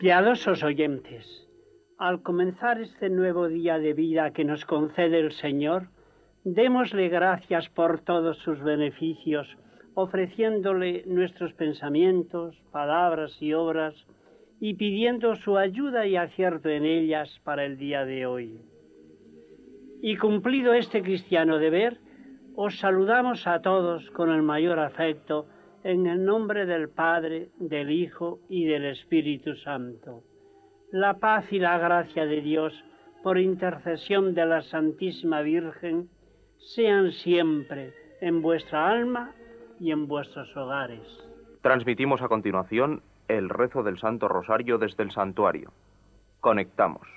Paraules religioses que precedeixen la transmissió del Rosari des d'un santuari
Fragment extret del programa "La radio con botas", emès per Radio 5 de RNE l'any 1991